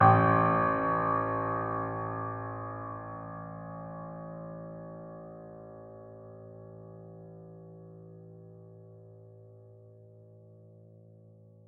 Steinway_Grand